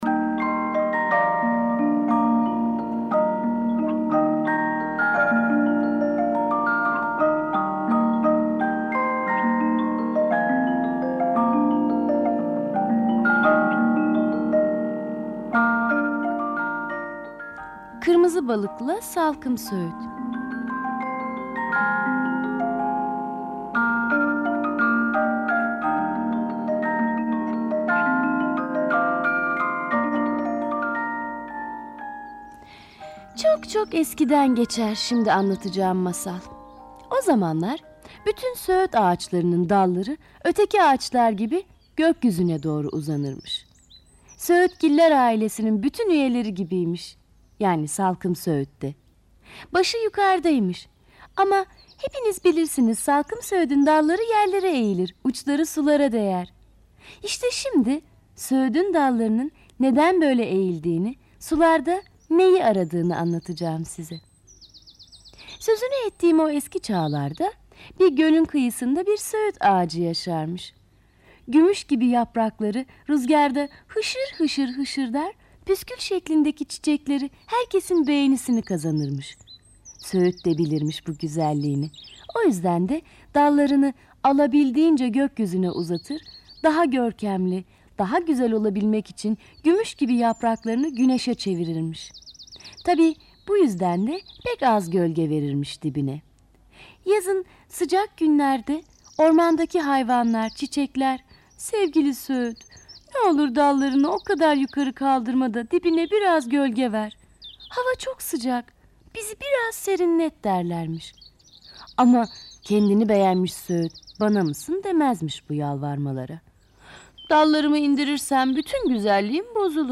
Kategori Sesli Çocuk Masalları